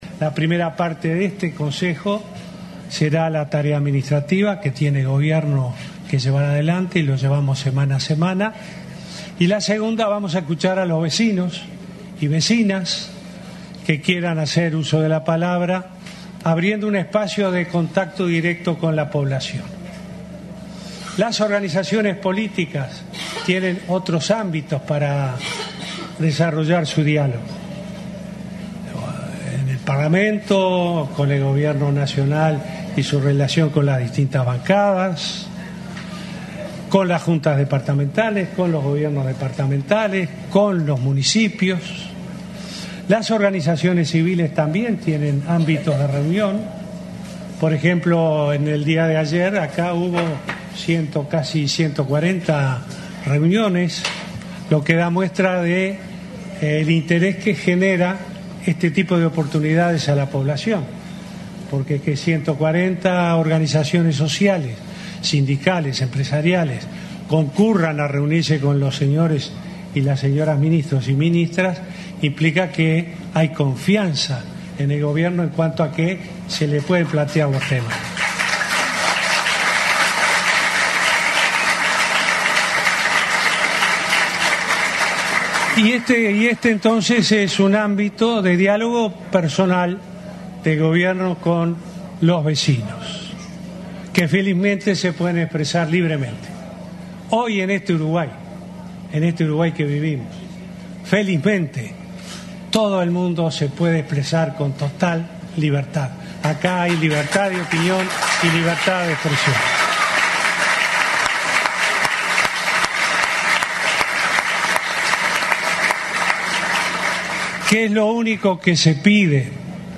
Unas 140 organizaciones sociales se reunieron con las autoridades del Gobierno en la jornada previa al Consejo de Ministros de este lunes en Playa Pascual, San José. Este hecho implica que hay confianza en que al Gobierno se le pueden plantear temas, resaltó el presidente Tabaré Vázquez. Acá hay libertad de opinión y de expresión, no como en otras épocas, aseguró.